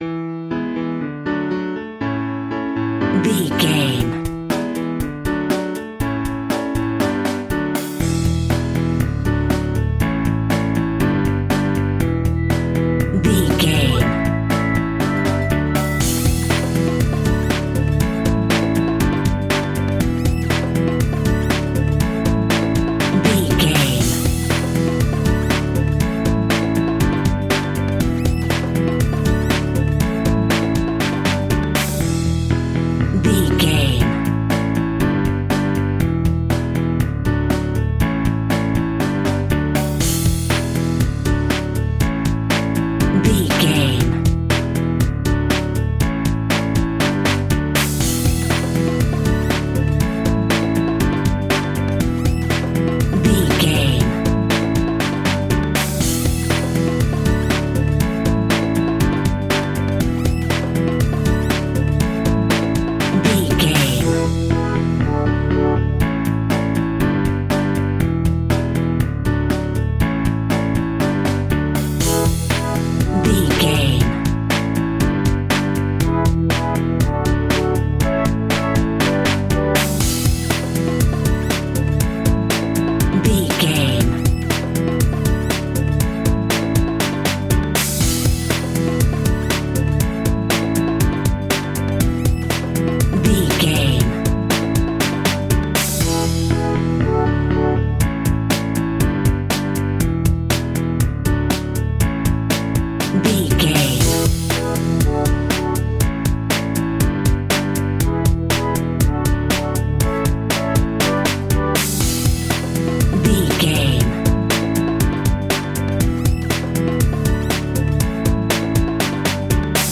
Ionian/Major
D
pop rock
fun
energetic
uplifting
drums
bass guitar
piano
hammond organ
synth